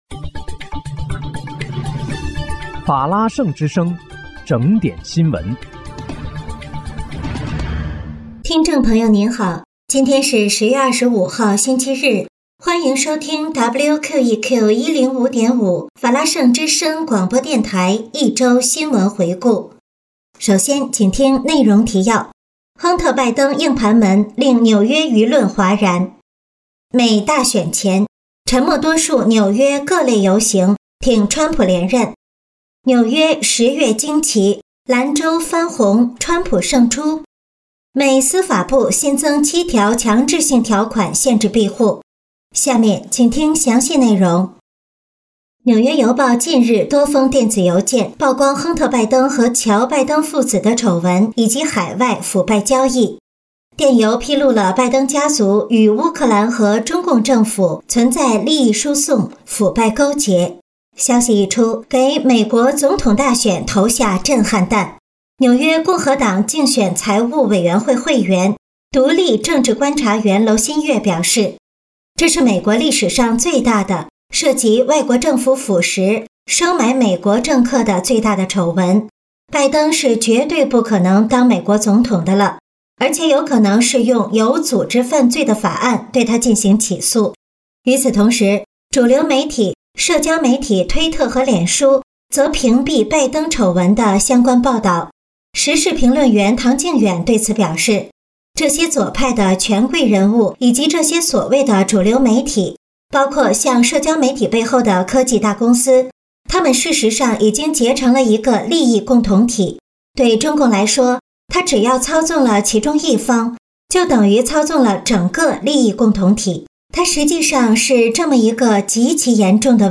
10月25日（星期日）一周新闻回顾